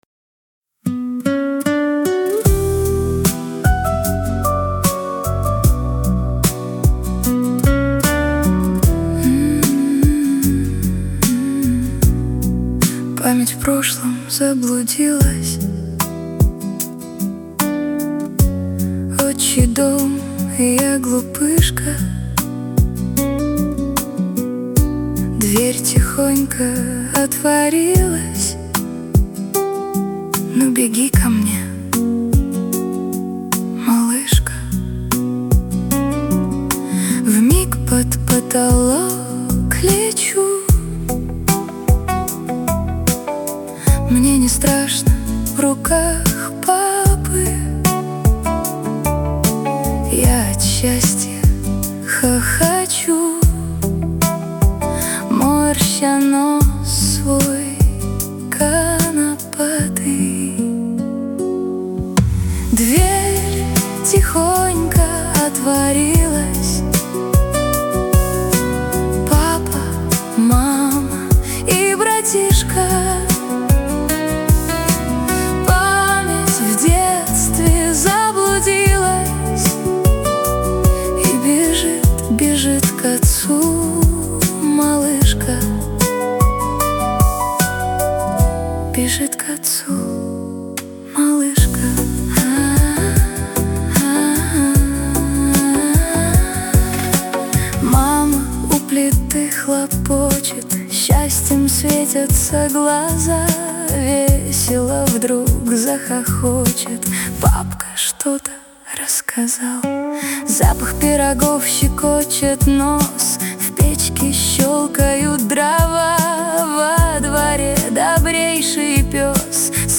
Лирика
pop